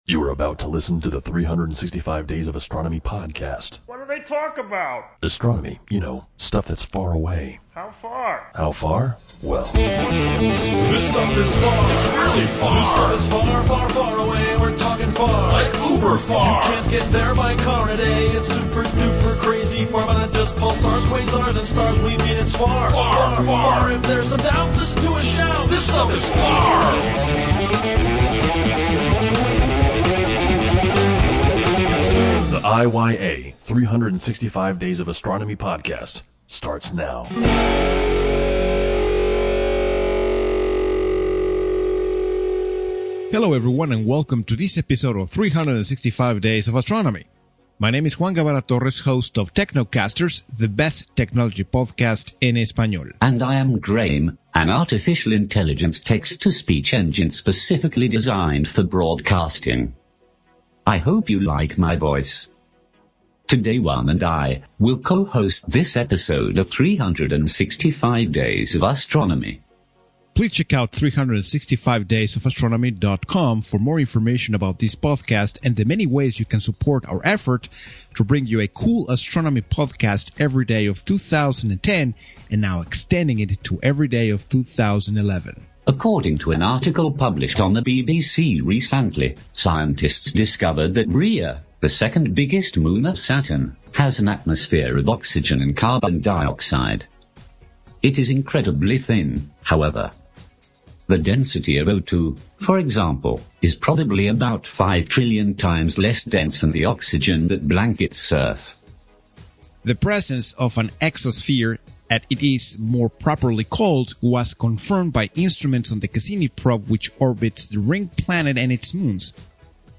el primer locutor basado en Inteligencia Artifical